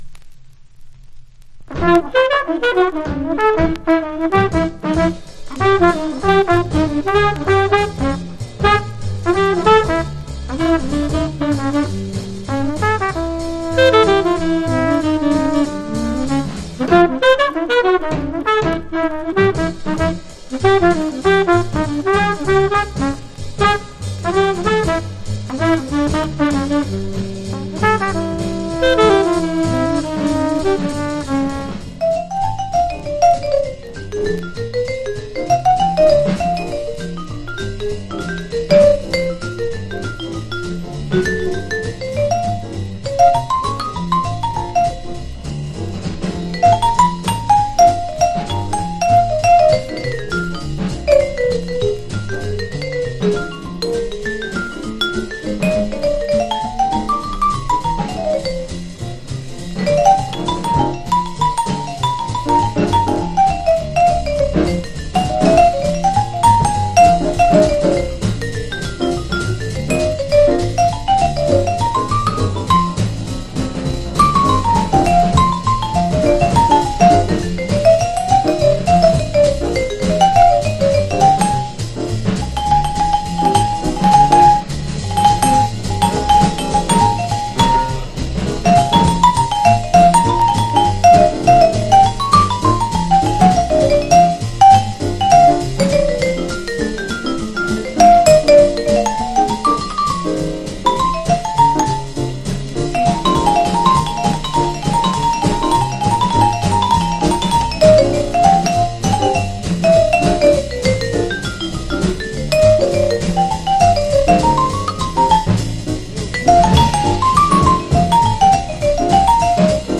（MONO針で聴くとほとんどノイズでません）
Genre US JAZZ